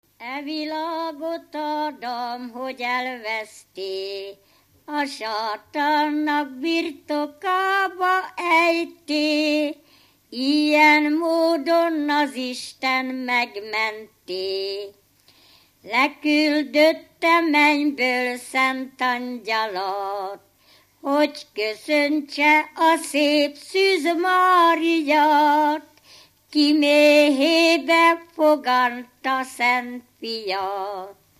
Felföld - Bars vm. - Barslédec
ének
Stílus: 9. Emelkedő nagyambitusú dallamok
Szótagszám: 10.10.10
Kadencia: 1 (5) 1